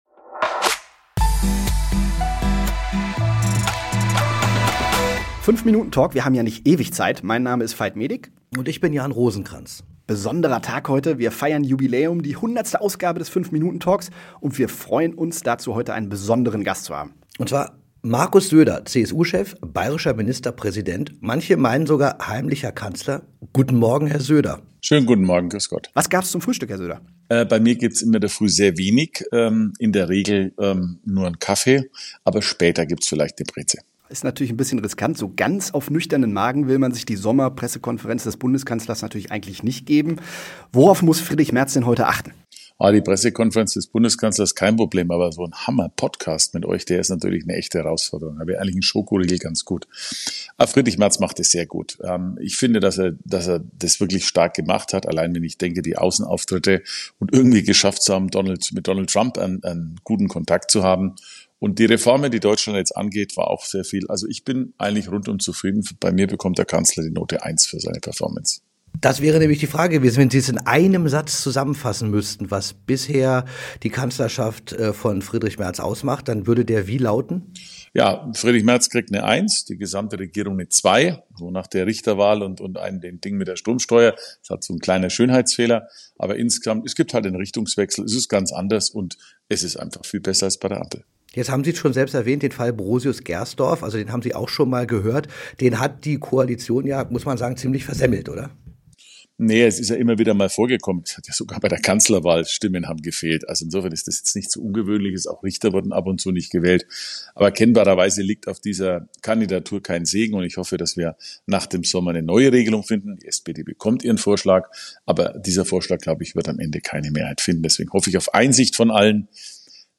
gemeinsam mit CSU-Chef Markus Söder Bilanz über die ersten Monate